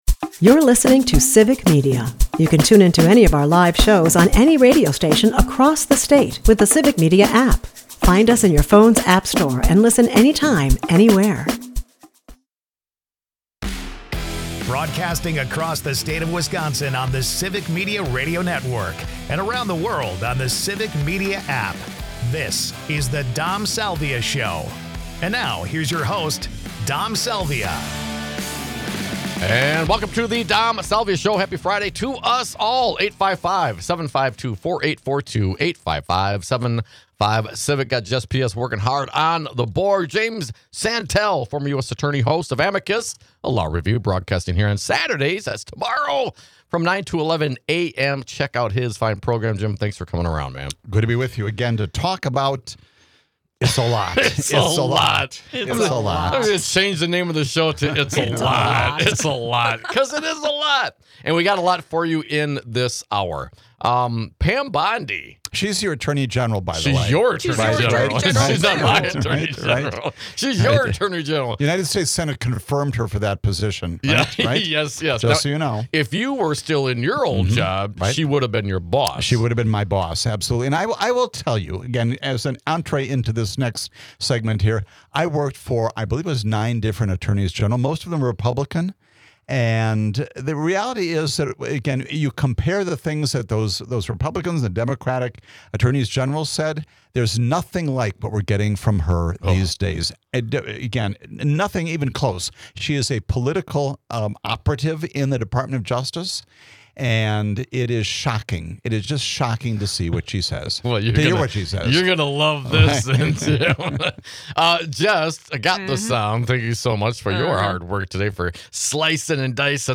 Jim Santelle joins us to discuss the checks and balances that keep our democracy healthy, and what to do when they aren't respected.